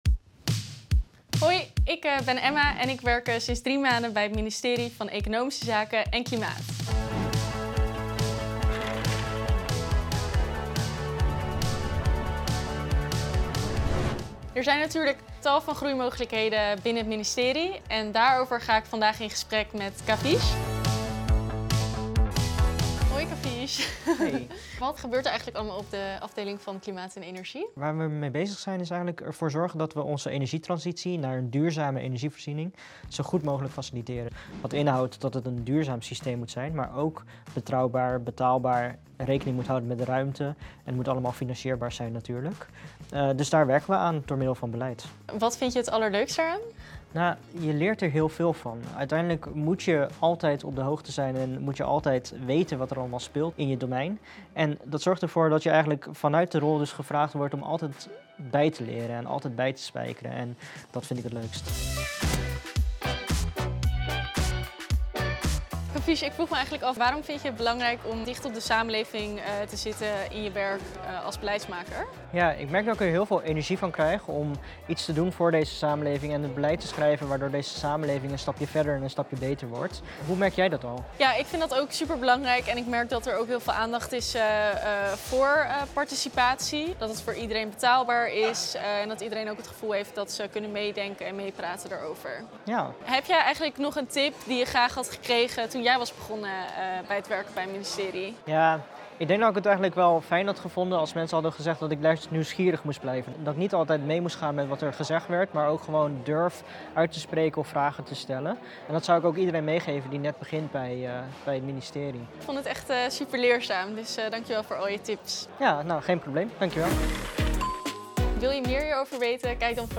In de videoserie Op Je Plek Bij gaan starters in gesprek met ervaren collega’s over het werken en de loopbaanmogelijkheden bij het ministerie van Economische Zaken (EZ, voorheen het ministerie van Economische Zaken en Klimaat).